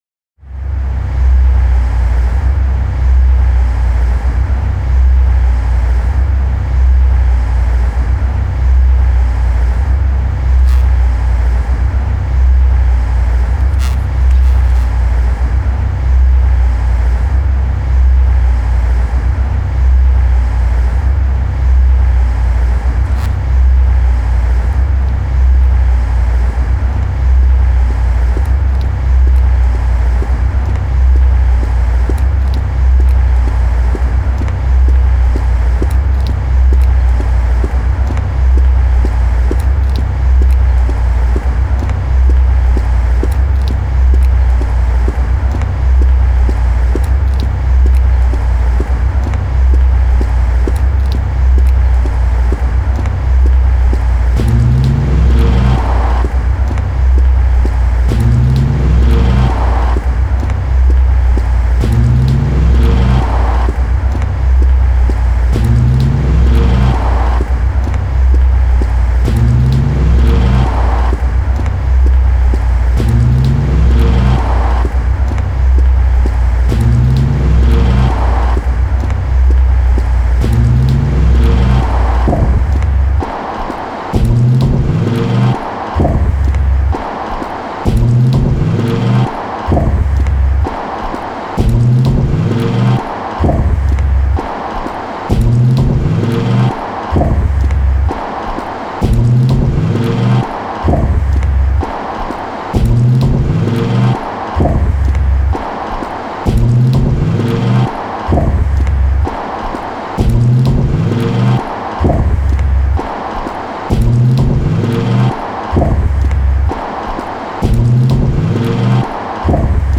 Audio non-musical
interview